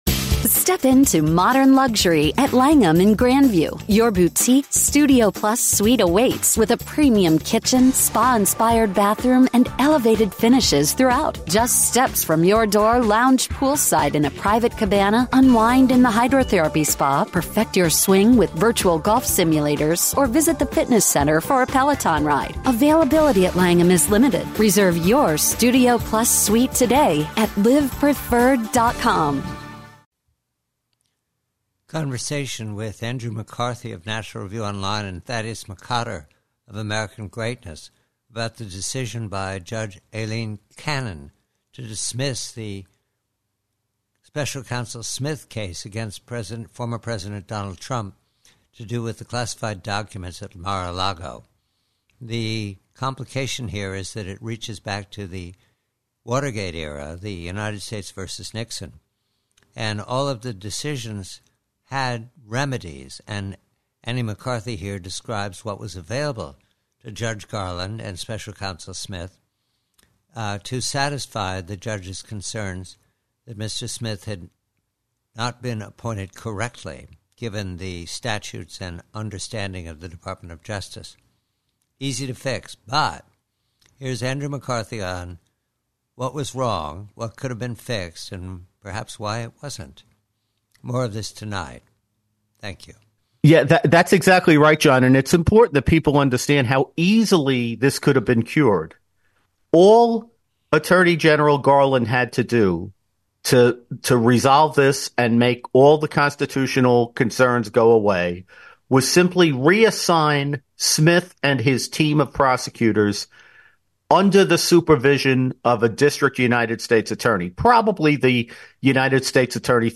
PREVIEW: DOJ: TRUMP: Conversation with colleague Andrew McCarthy